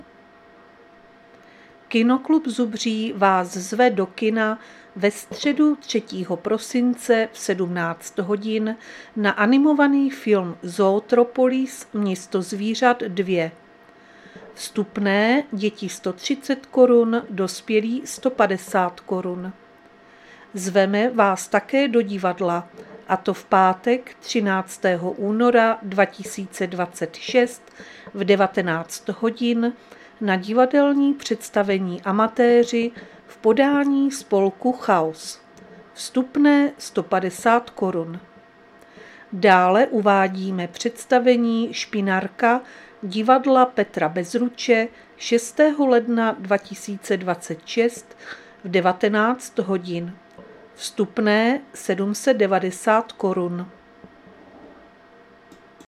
Záznam hlášení místního rozhlasu 3.12.2025